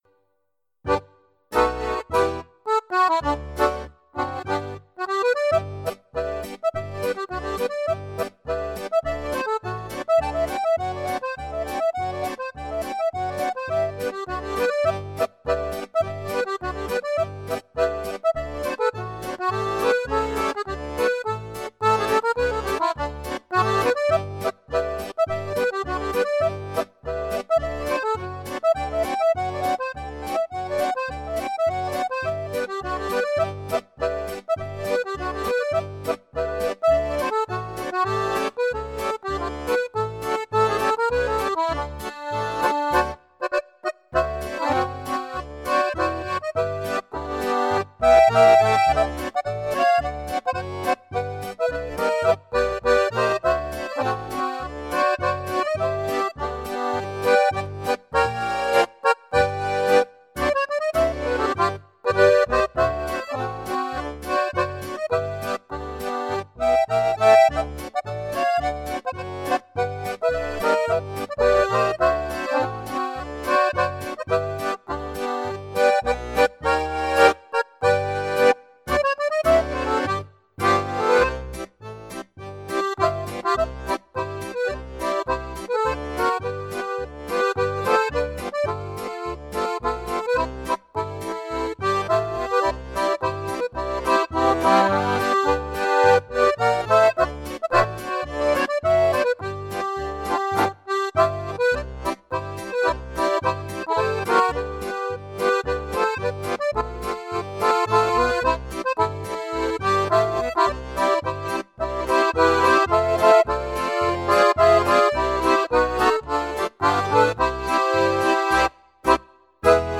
aber schön gespielt.